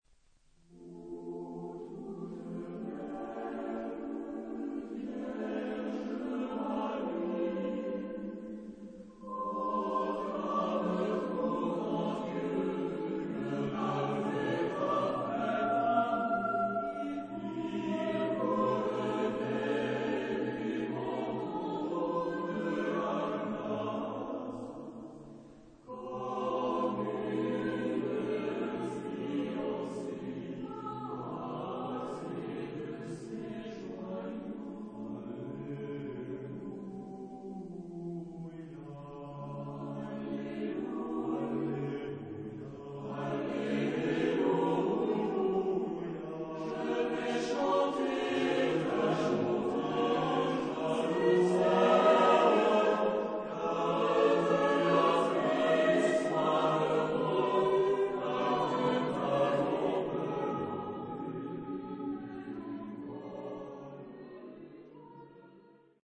Genre-Style-Forme : Hymne (sacré) ; Sacré
Caractère de la pièce : andante
Type de choeur : SATB (+div.)  (4 voix mixtes )